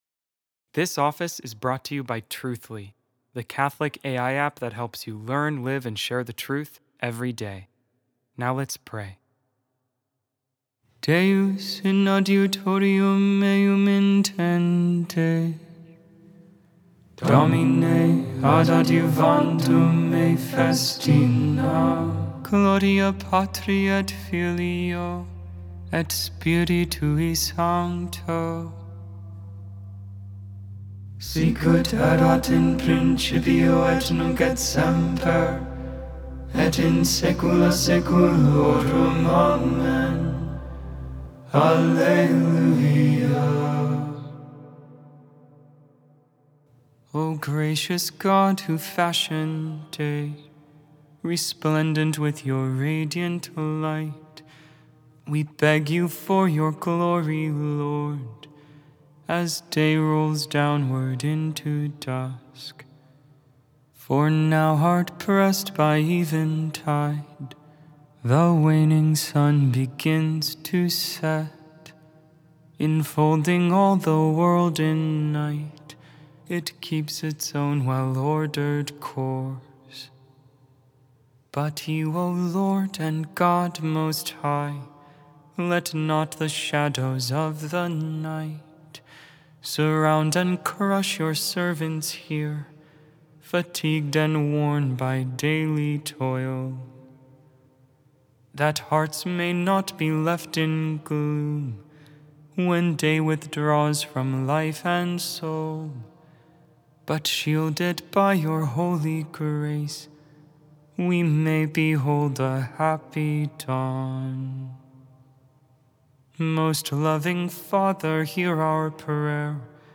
Vespers, Evening Prayer for the 10th Thursday in Ordinary Time, August 7, 2025.
Ambrosian Hymn - Frankish Hymnal c. 700-800 AD.